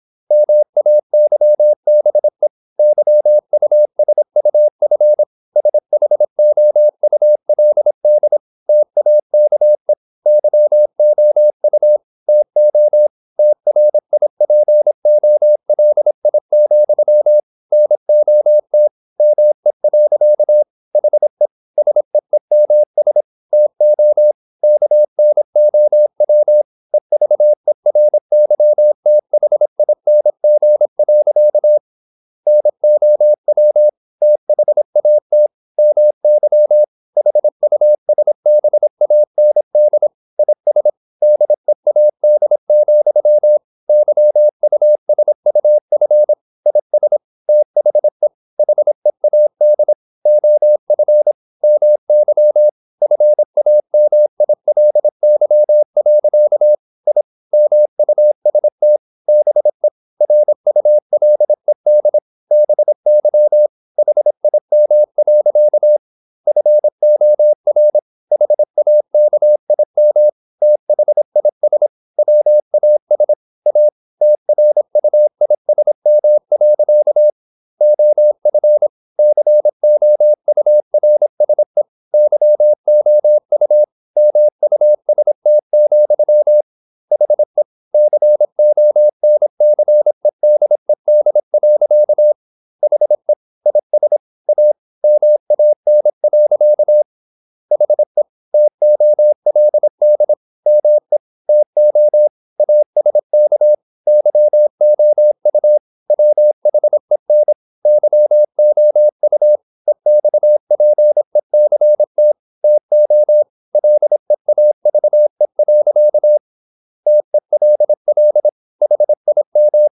Never 26wpm | CW med Gnister